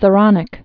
(sə-rŏnĭk)